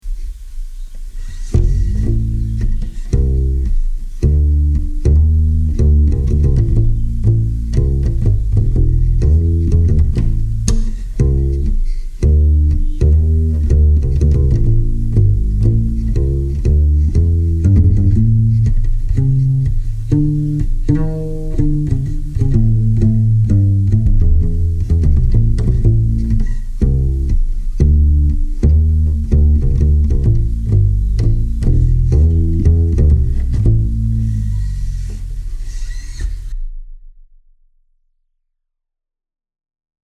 Half-Cello-Bass
It has all of the distinctive woodyness and fast string decay of the typical full size upright bass, and at just under 8lbs.
Acoustically, it sounds very, very much like an upright bass, but, of course, lacks the booming bottom end volume of a full sized upright bass.
Here's an acoustic sound clip. This is through a 58 sitting on my desk, aimed at the bridge, about 16" away, into Logic, via a FastTrack, with a slight bass EQ bump.
half-cello-track-1.mp3